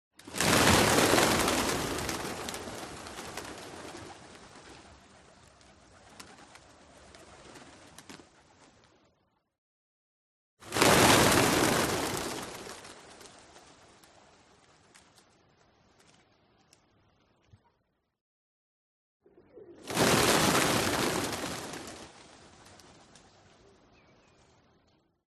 Звуки голубя
стая голубей взмывает с земли